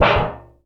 metal_tin_impacts_hit_hard_02.wav